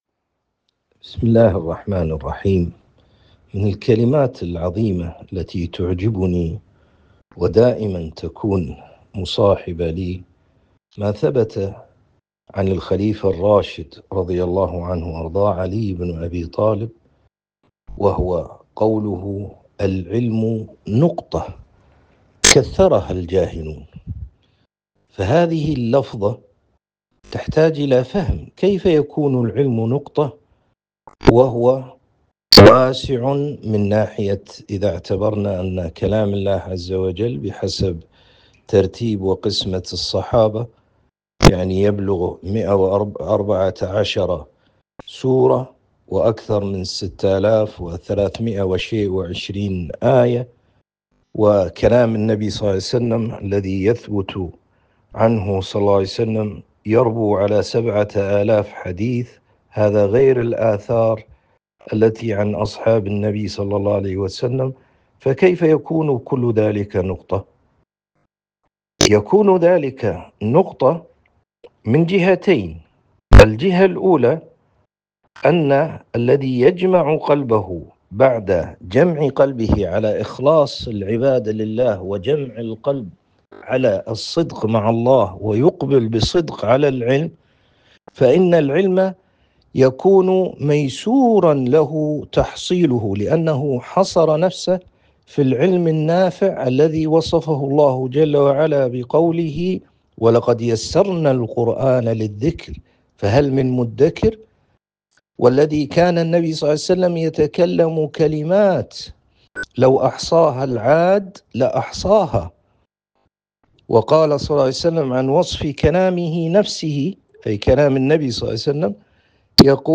MP3 Mono 22kHz 65Kbps